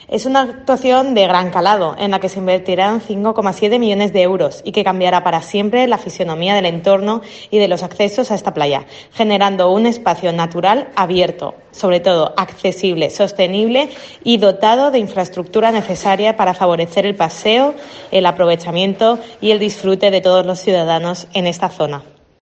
Rocío Gómez, edil de Urbanismo